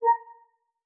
click_button.wav